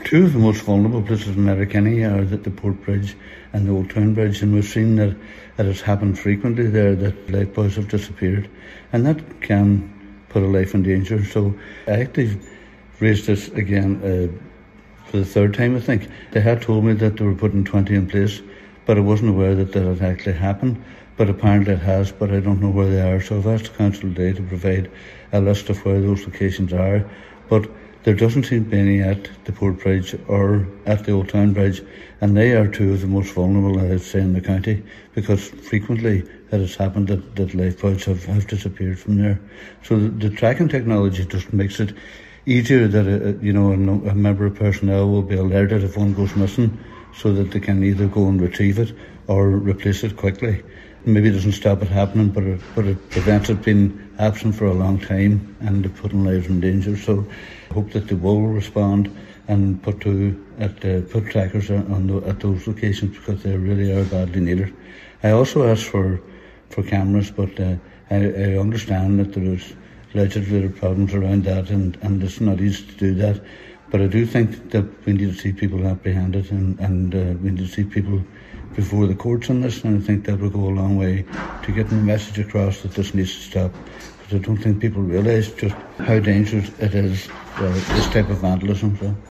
Councillor Jimmy Kavanagh however, is urging Donegal County Council to ensure the smart technology trackers are rolled out across the entire county: